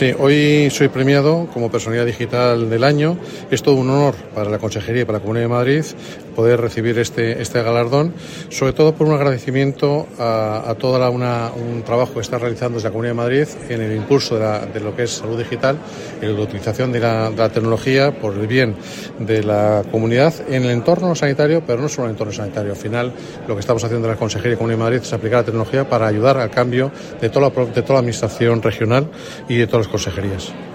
Declaraciones del Consejero]